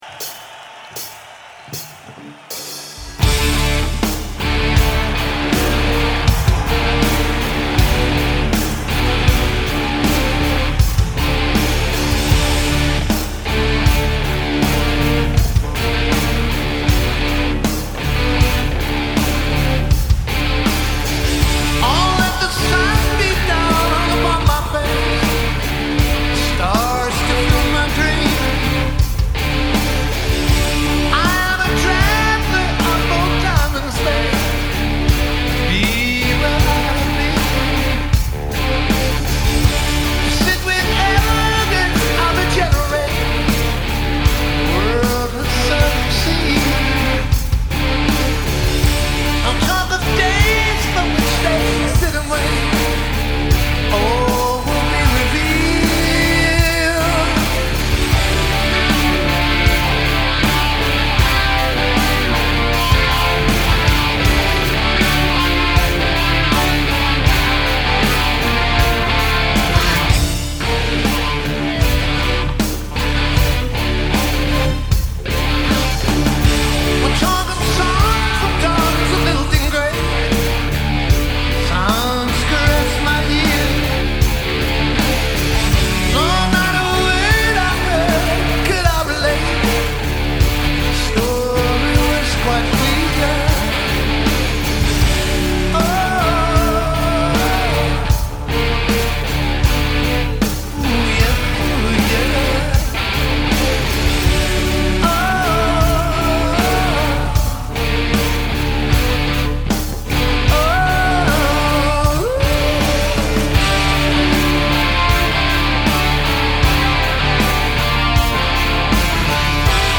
concierto realizado en el 2007 en el O2 Arena de Londres